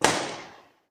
FeelTacticalRifle.wav